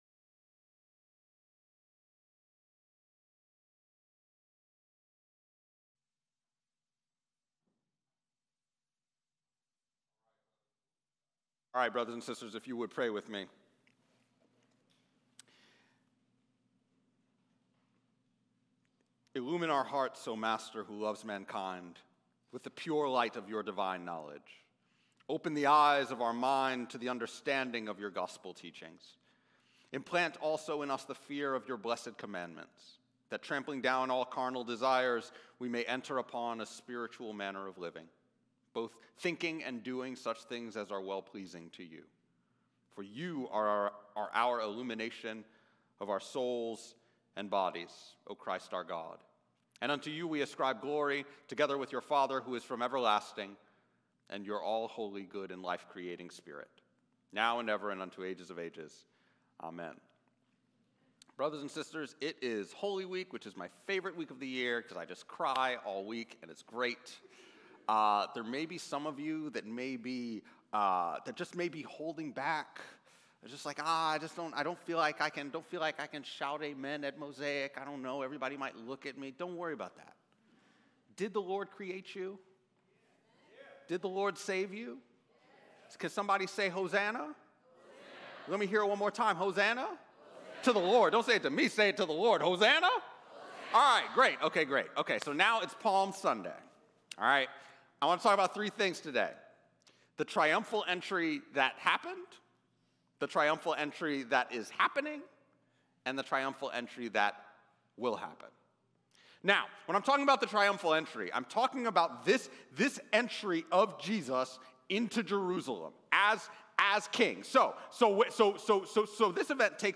11AM Service Mar 29th 2026